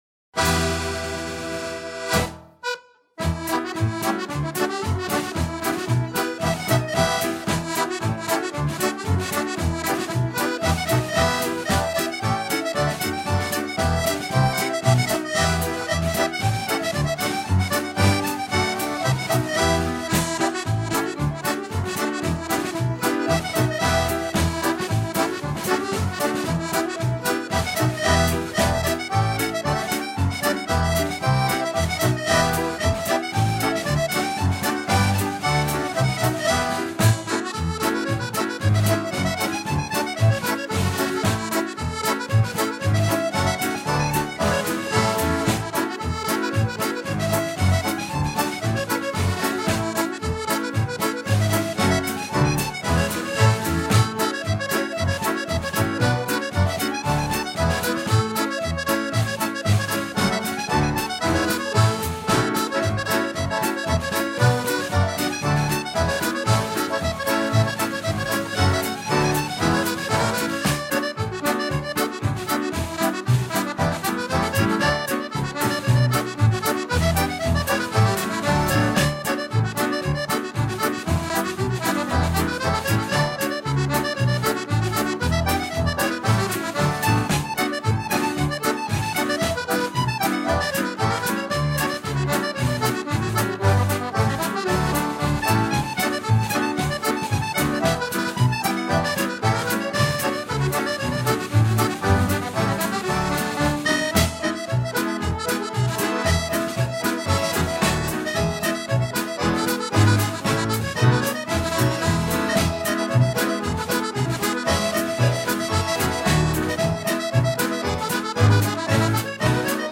Genre: World Pop.